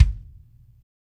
Kicks
DrKick90.wav